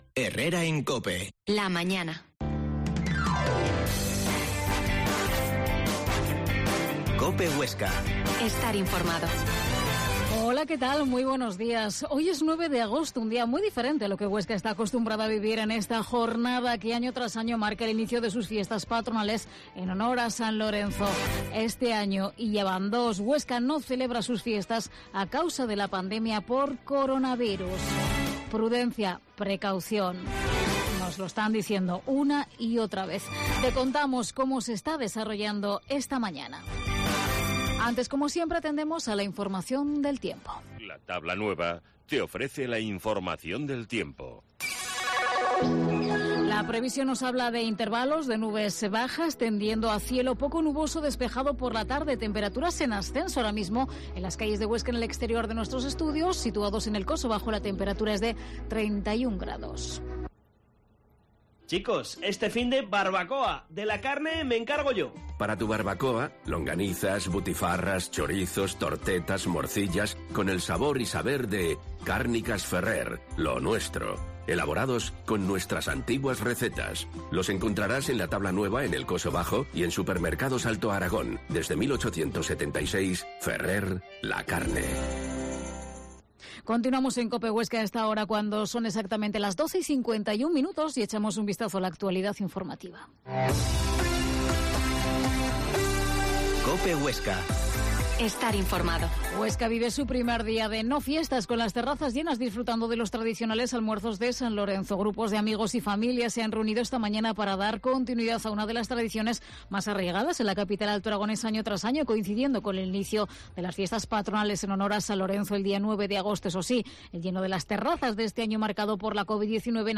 Herrera en COPE Huesca 12.50h. Entrevista al Alcalde de Huesca, Luis Felipe